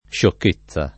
sciocchezza [ + šokk %ZZ a ] s. f.